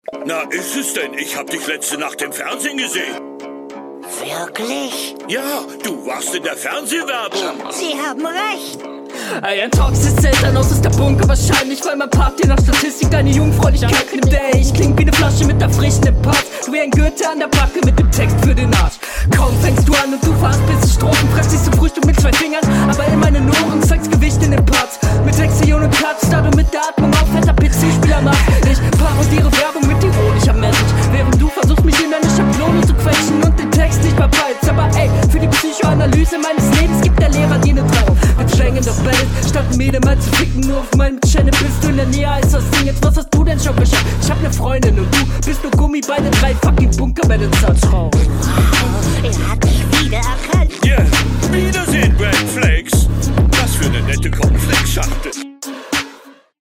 Teilweise schwer zu verstehen, Konter sind meist eher ungut aber textlich besser als die HR.
das klingt zu sehr nach einem onetake der kein onetake hätte werden sollen